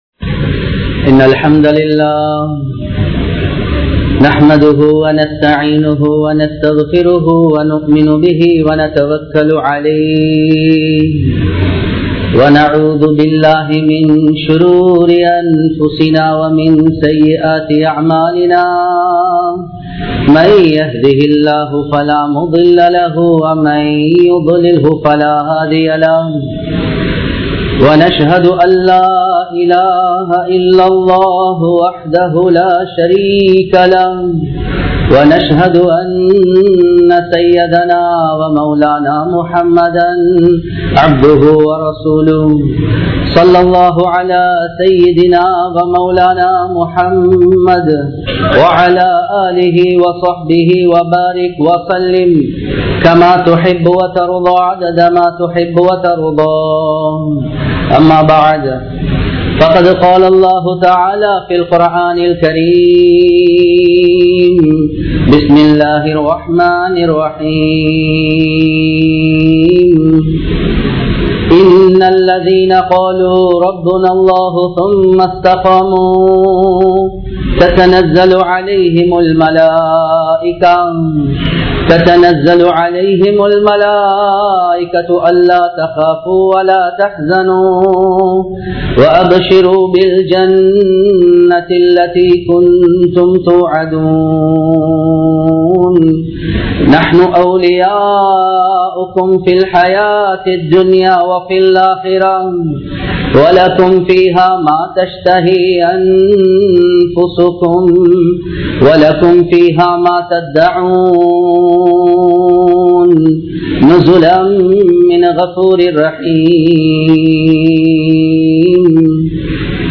Muharram Maathathin Sirappuhal (முஹர்ரம் மாதத்தின் சிறப்புகள்) | Audio Bayans | All Ceylon Muslim Youth Community | Addalaichenai
Muhideen (Markaz) Jumua Masjith